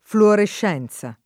[ fluorešš $ n Z a ]